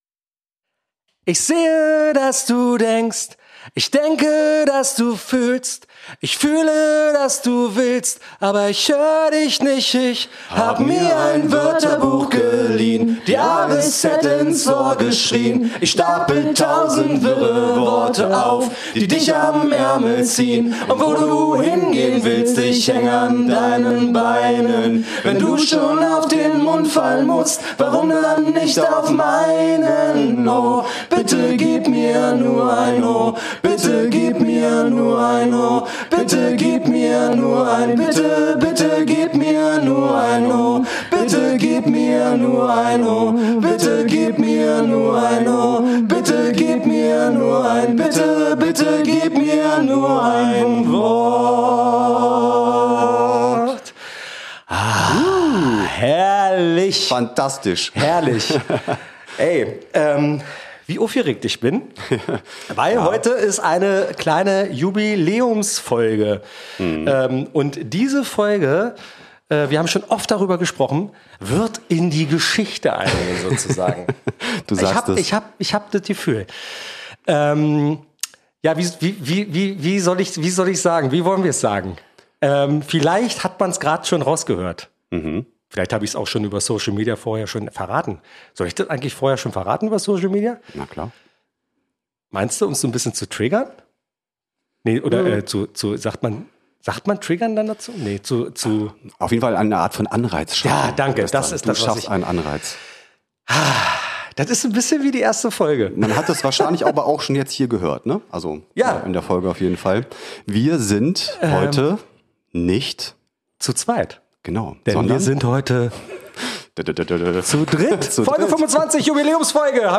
Darüber hinaus haben sich die Drei einem Thema gewidmet das nie "alt" wird: Wie bleibt man eigentlich authentisch? Ein Gespräch mit neuen Blickwinkeln und ehrlichen Gedanken.